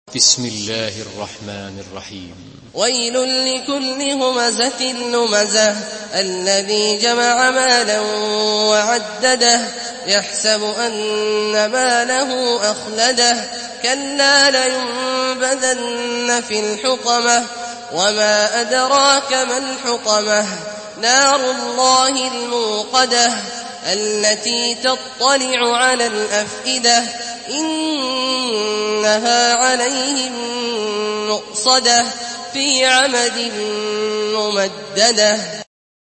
Surah Al-Humazah MP3 by Abdullah Al-Juhani in Hafs An Asim narration.
Murattal Hafs An Asim